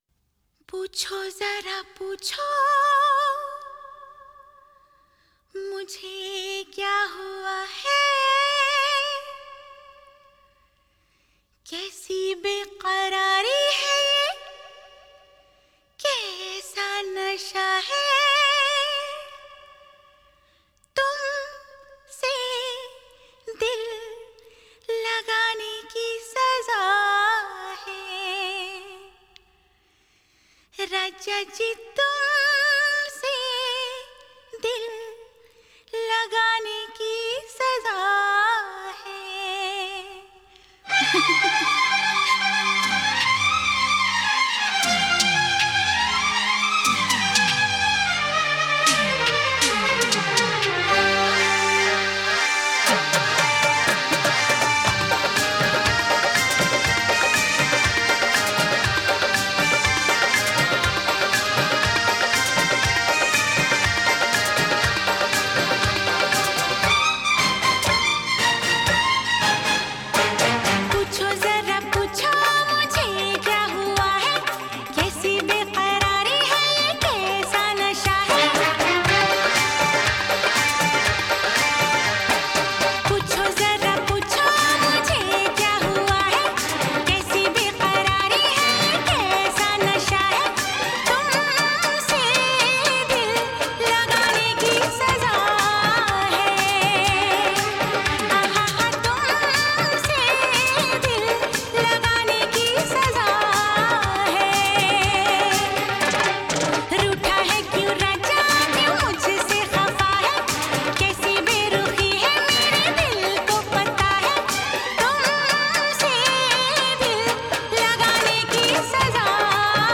original soundtrack
Bollywood track